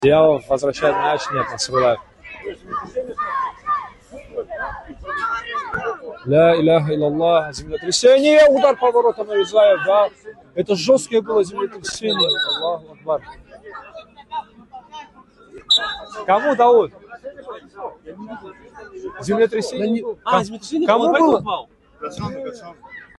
В Дагестане началось землетрясение магнитудой 6 баллов прямо во время футбольного матча между командами «Магма» и «Азерпетрол». Один из игроков не устоял на ногах, а остальные после подземных толчков продолжили катать мяч, будто ничего и не произошло. Комментатор — маэстро.